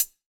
HiHat (5).wav